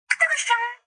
电脑效果 " 铃声。眩晕
描述：在GarageBand中创建的2.5秒铃声
Tag: 警报 铃声 警报 铃声 手机